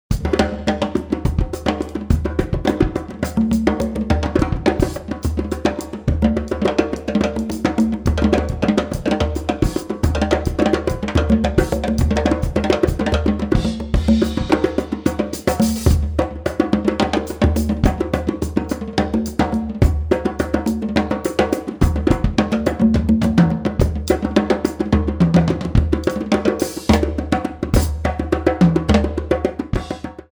Fast Tempo
congas & djembe
accordion
violin